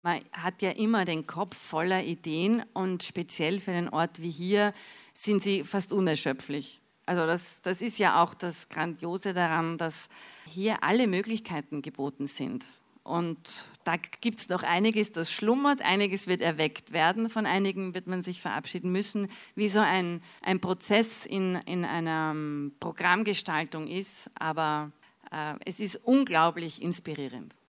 O-Ton Pressekonferenz "Bregenzer Festspiele ab 2015" - news